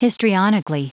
Prononciation du mot histrionically en anglais (fichier audio)
Prononciation du mot : histrionically